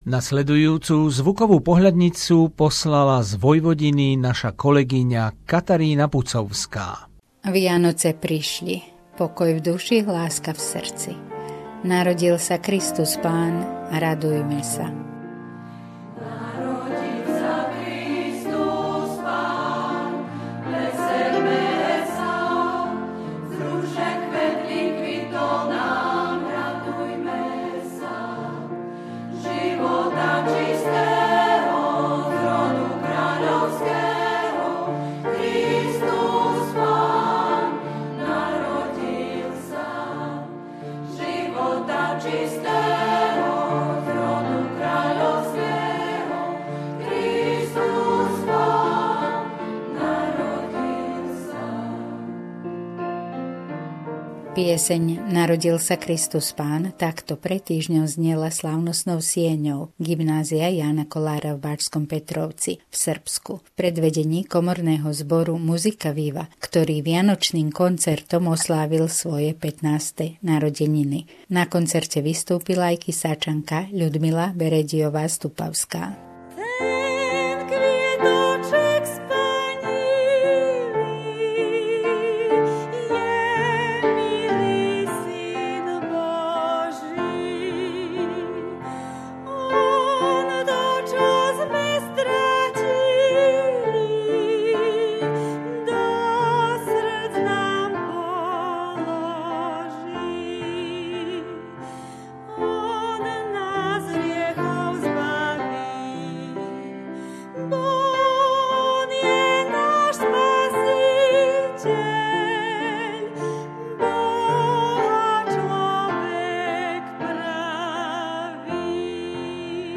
Vianočná zvuková pohľadnica